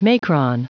Prononciation du mot macron en anglais (fichier audio)